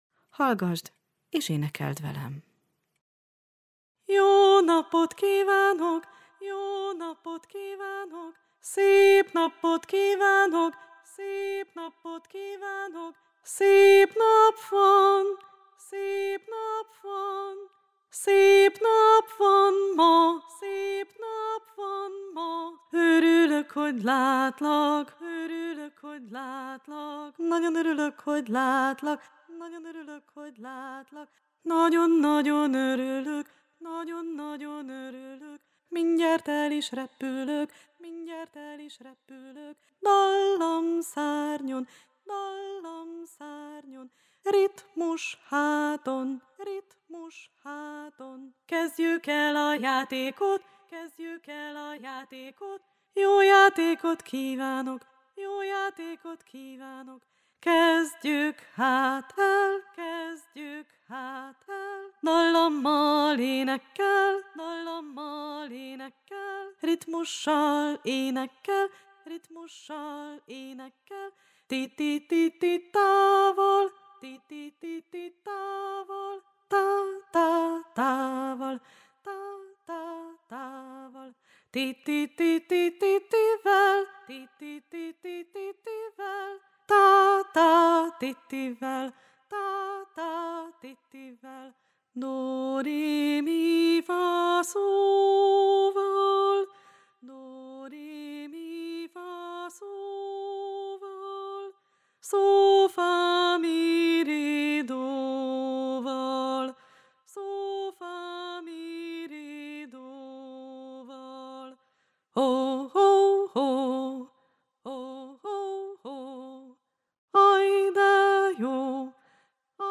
JÓ NAPOT mondóka _ Felelgető
141_jonapot_mondoka_felelgeto_szoveggel.mp3